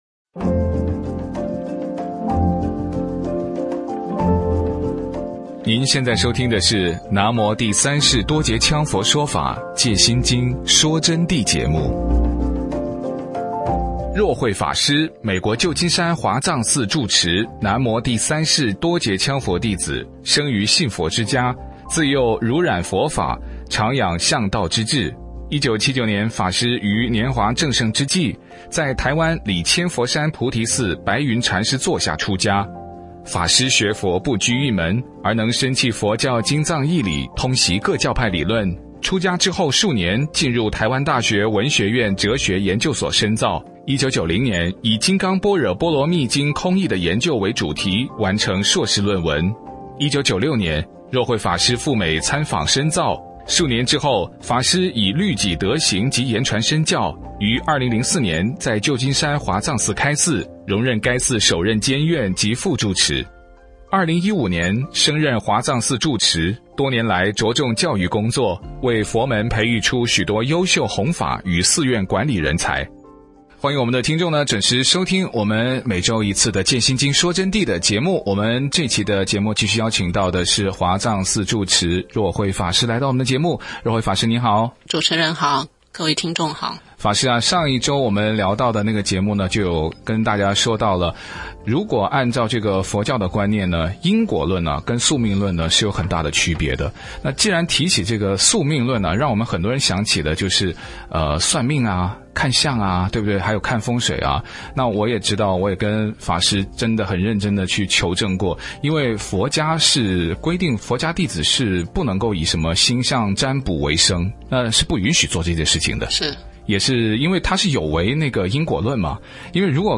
佛弟子访谈（十四）因果与宿命论的差别